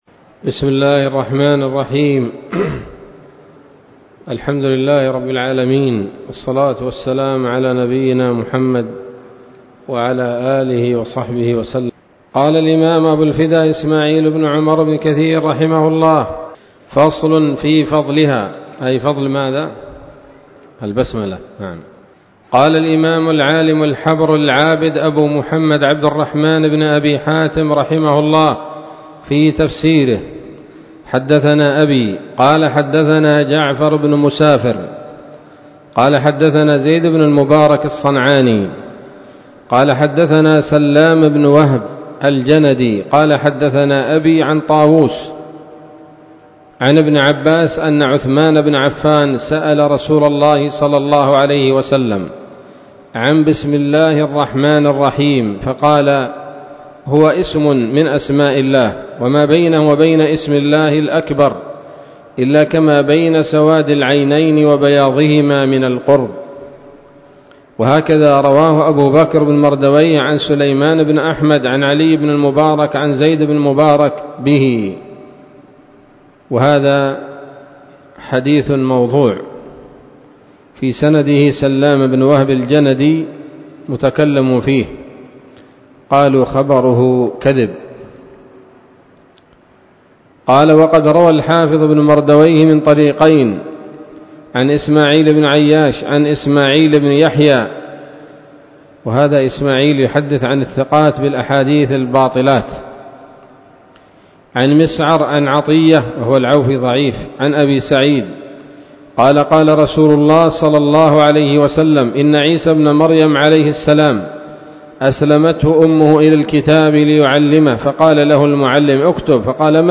الدرس العاشر من سورة الفاتحة من تفسير ابن كثير رحمه الله تعالى